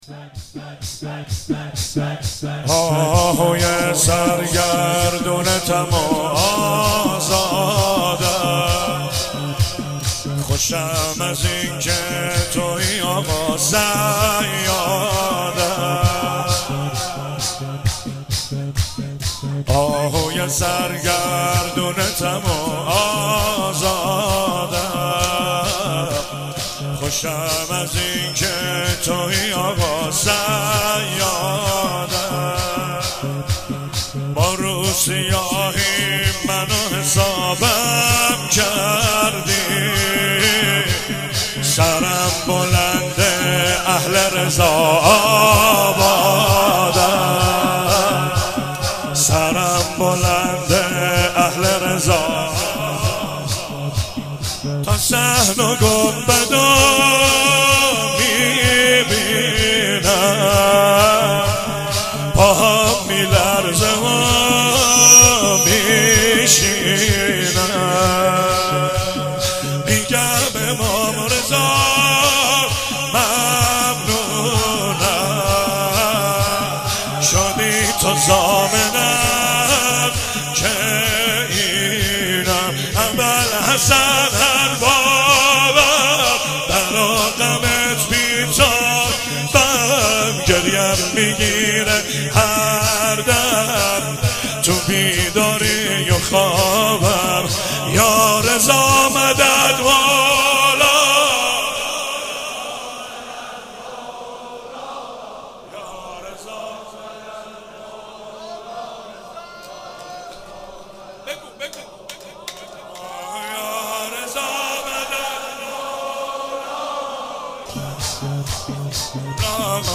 قالب : شور